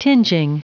Prononciation du mot tinging en anglais (fichier audio)
Prononciation du mot : tinging